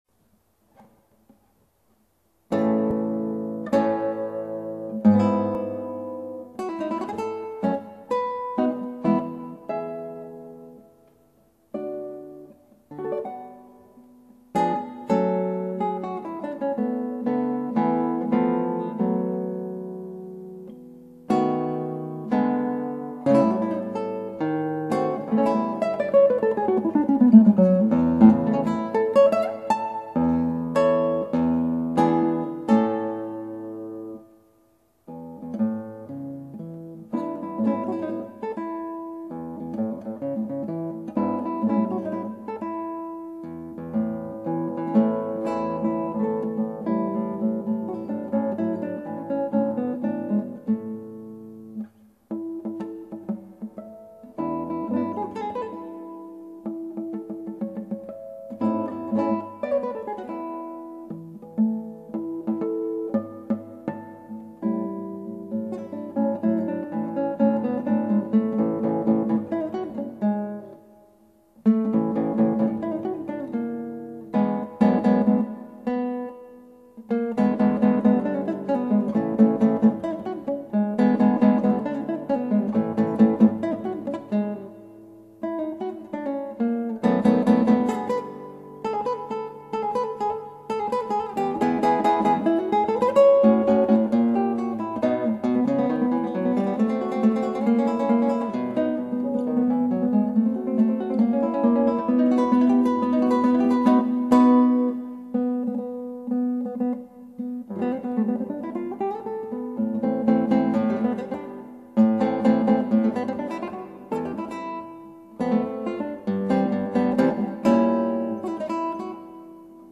クラシックギター　ストリーミング　コンサート
古典練習風景　編
途中止まりまくりだしちゃんと最後まで弾いてないです。
途中からやけになって弾いてるよ。
途中楽譜めくる音まで入っておもろいなぁ。